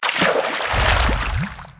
diving.ogg